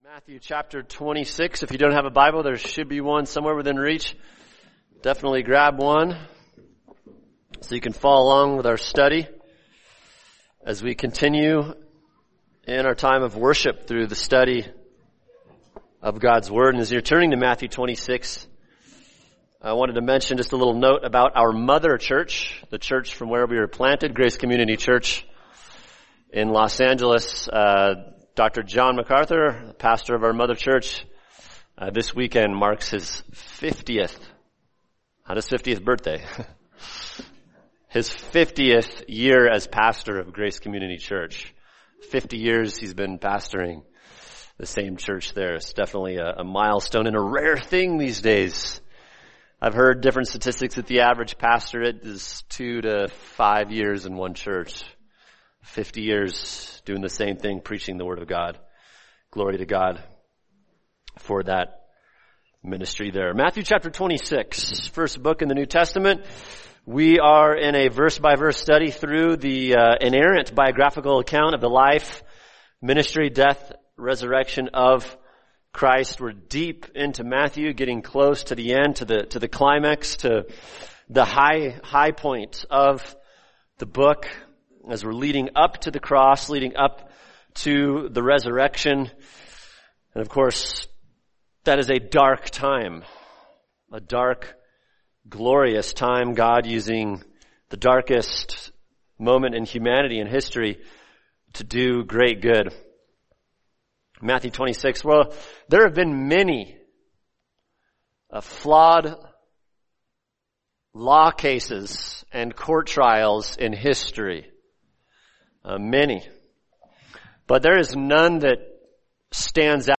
[sermon] Matthew 26:57-68 – Jesus in the Face of Corrupt Law | Cornerstone Church - Jackson Hole